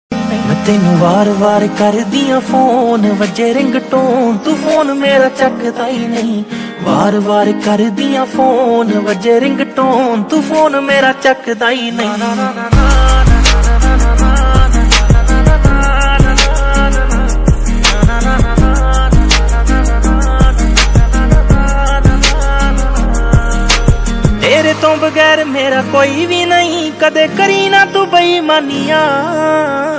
Category: panjabi song